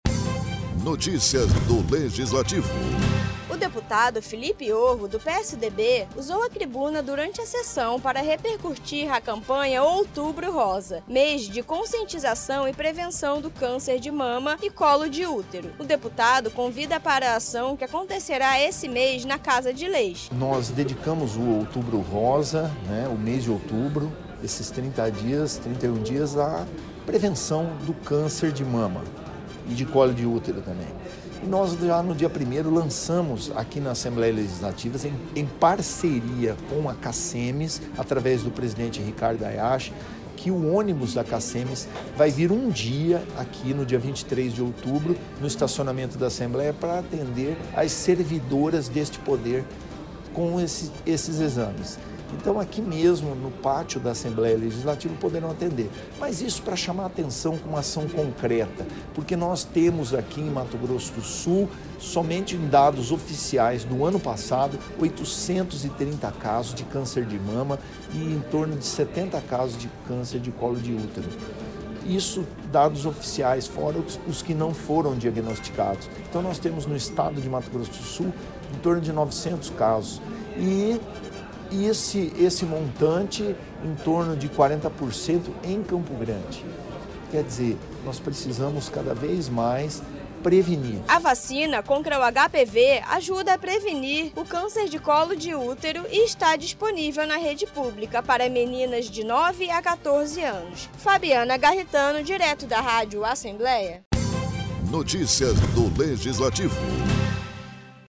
O deputado Felipe Orro, do PSDB usou a tribuna durante sessão ordinária para divulgar a campanha Outubro Rosa e falar sobre a preocupação com o combate ao câncer de mama e câncer de colo do útero.